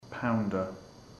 Προφορά
{‘paʋndər}